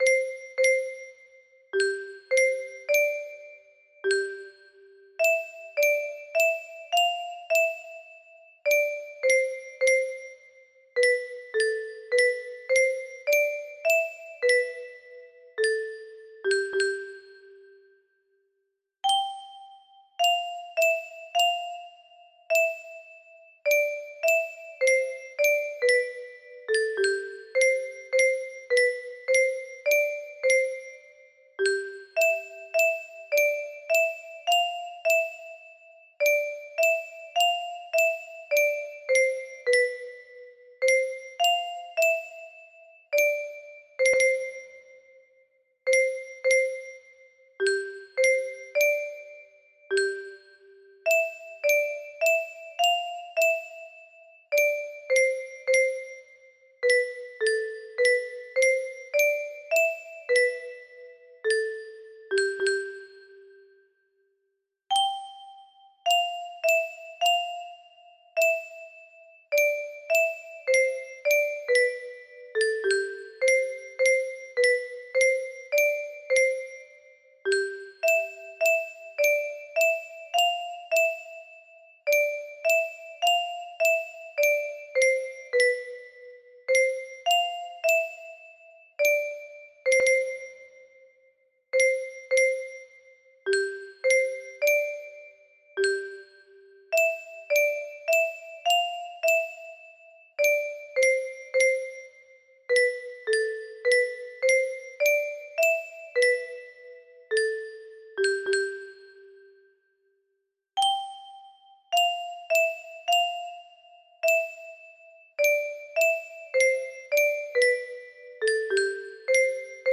Adeste fidelis music box melody